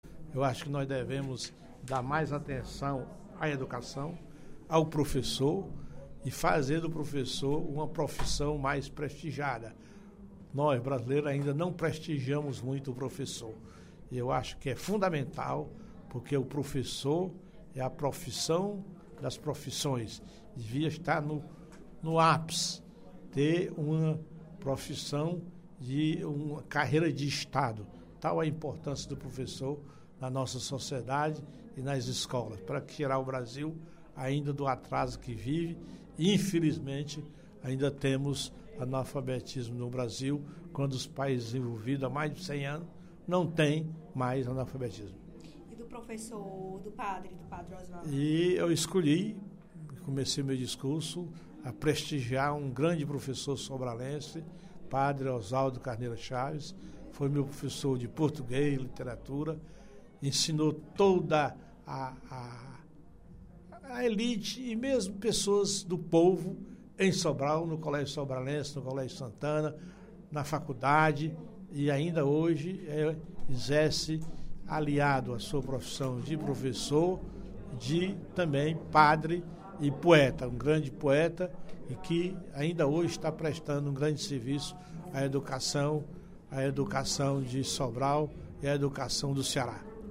Durante o primeiro expediente da sessão plenária, o parlamentar fez um histórico sobre a vida do padre, considerado pelo deputado “um dos maiores professores do Ceará”.